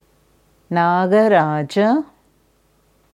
Sanskrit Nagaraj korrekte Aussprache anhören
Im altindischen Devanagari Skript wird es geschrieben नागराज, in der wissenschaftlichen IAST Transliteration nāga-rāja. Hier hörst du, wie man Nagaraj spricht.